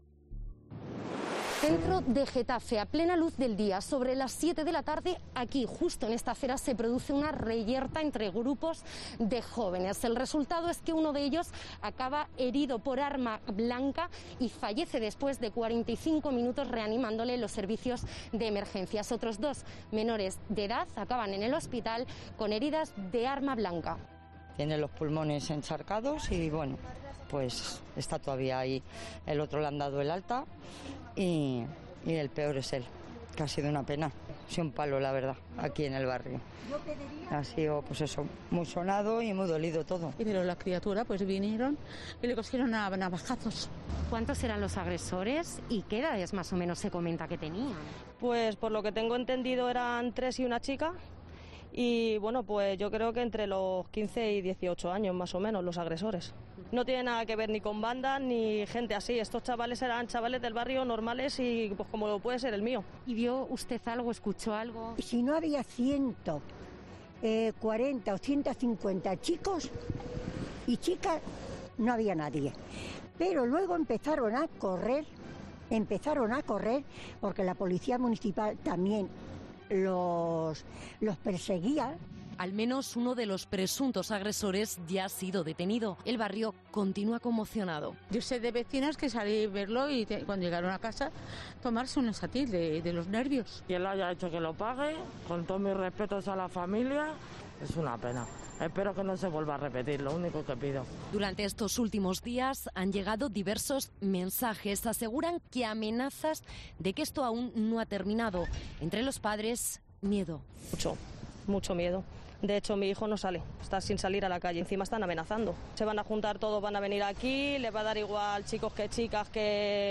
Reportaje completo del Programa de Ana Rosa